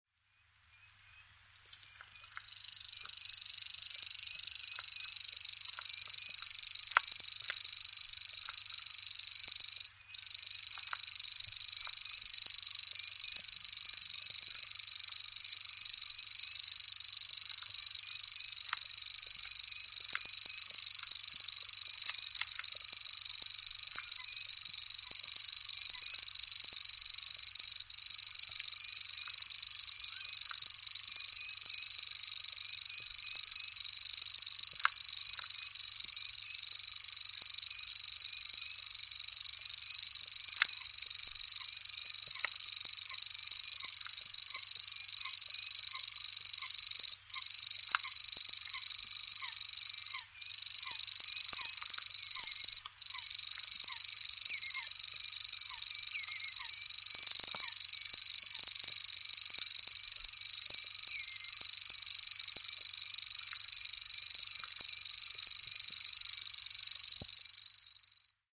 Grasshopper Warbler  Locustella naevia
Rainham Marshes, Essex, England  51º 29' 15.35'' N  00º 13' 22.7'' E   14 Apr 2016, 23:15h
Territorial reeling song of a bird perched in a small hawthorn next to reedy channel.